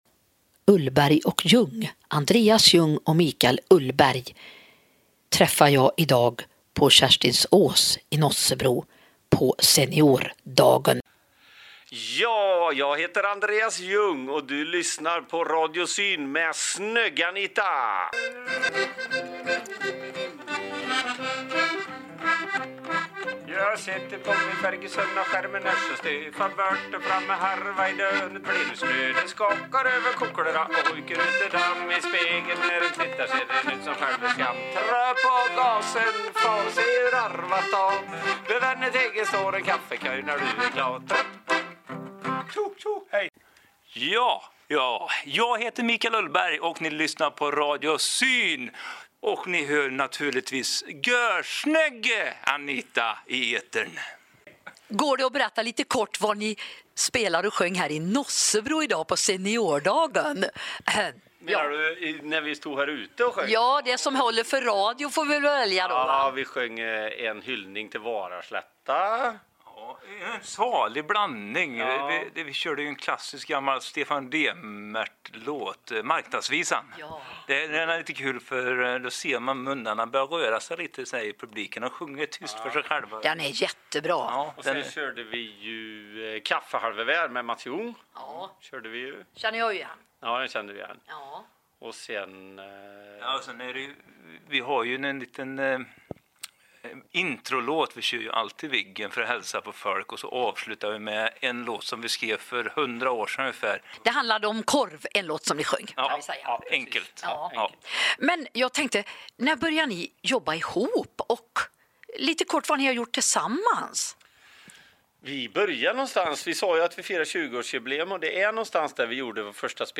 Foto på oss under intervjun, vi sjunger och grattar Radio Syn 30 år!
Härlig intervju. Älskar dialekten.